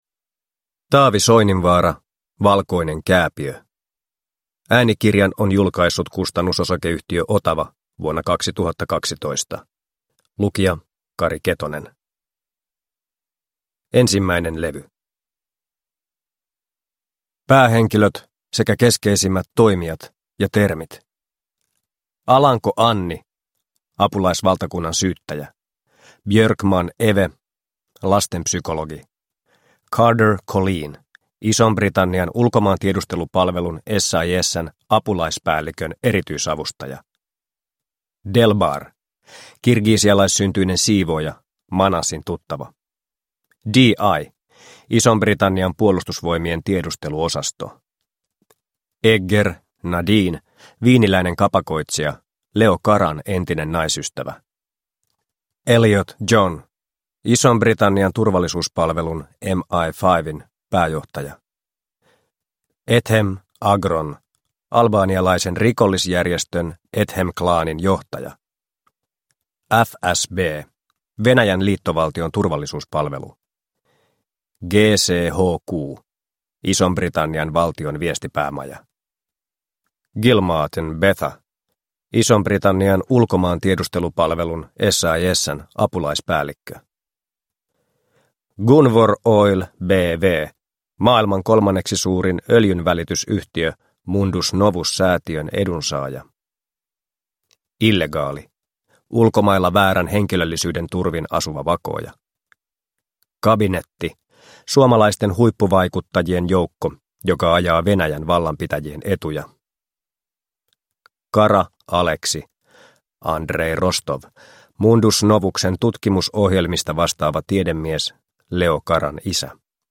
Valkoinen kääpiö – Ljudbok – Laddas ner